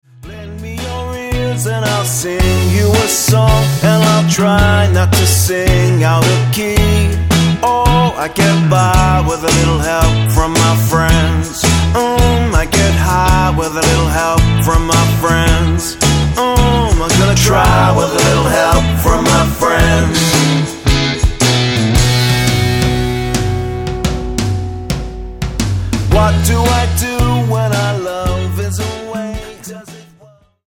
--> MP3 Demo abspielen...
Tonart:E Multifile (kein Sofortdownload.
Die besten Playbacks Instrumentals und Karaoke Versionen .